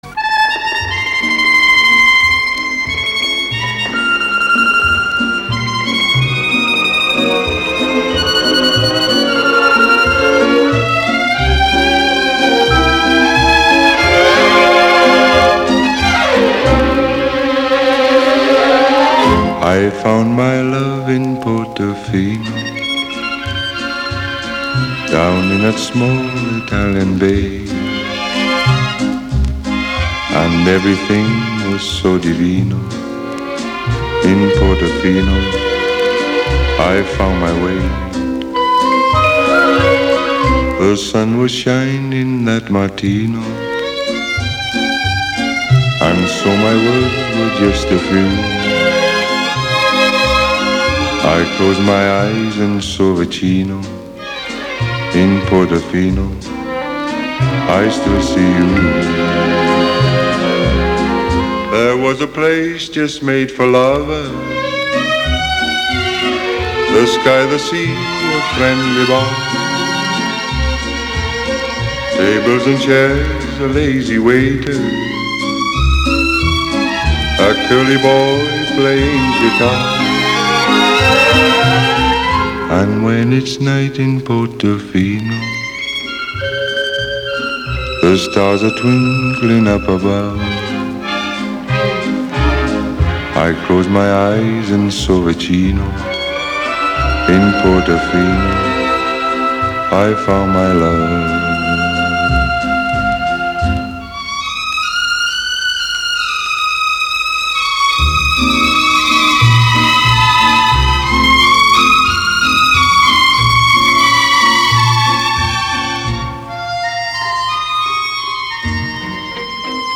booming baritone